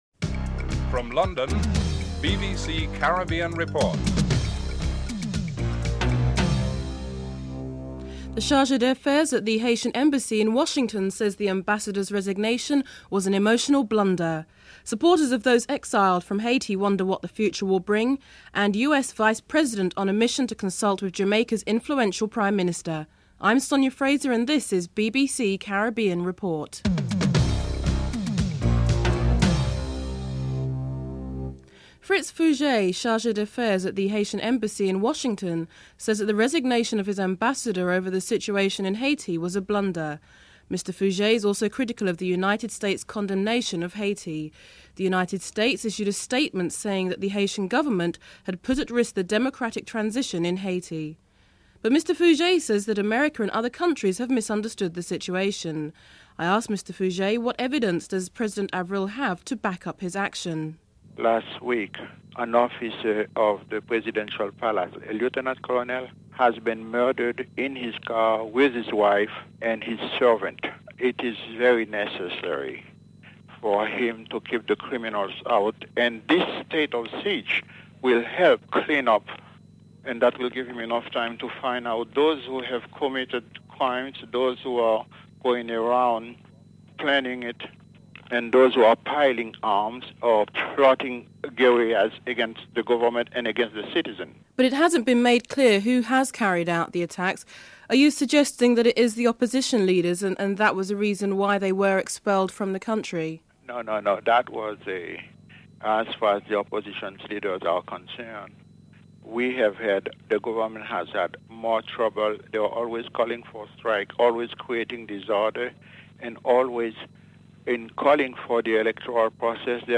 dc.description.tableofcontents4. Financial News.